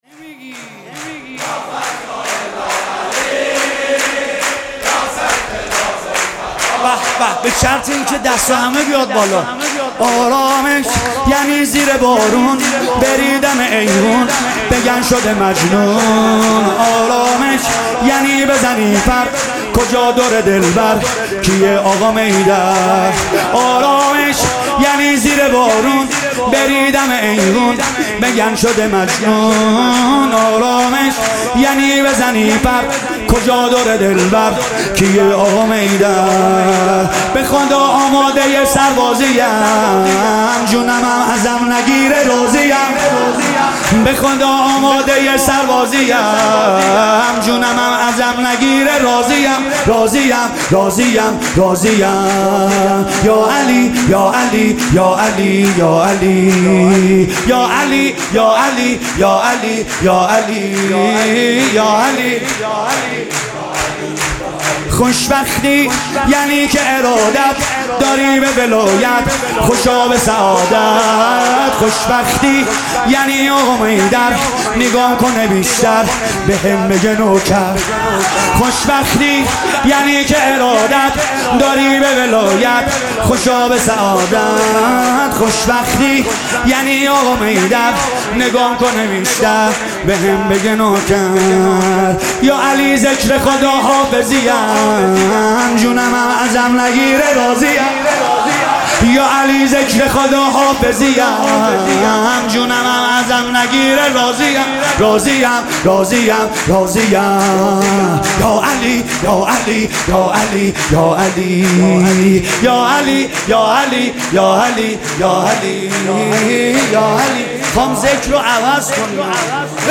ولادت امام علی (ع)
سرود